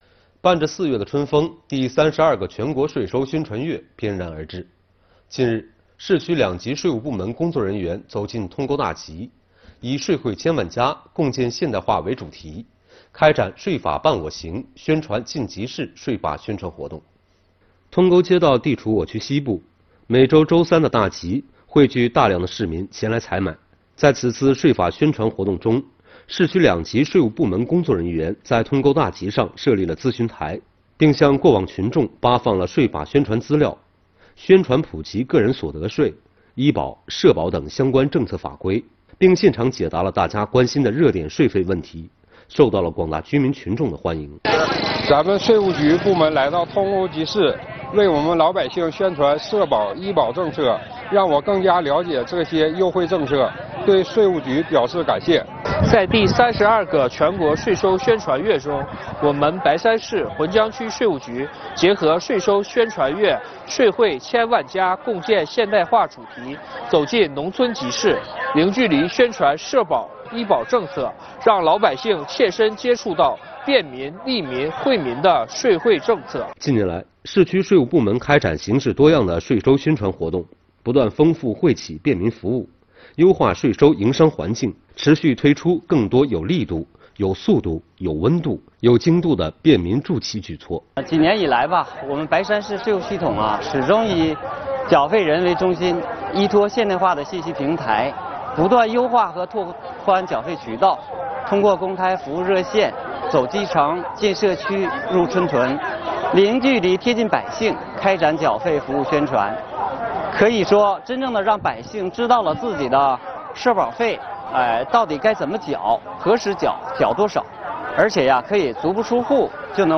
近日，白山市、区两级税务部门工作人员走进通沟大集，以“税惠千万家 共建现代化”为主题，开展“税法伴我行 宣传进集市”税法宣传活动。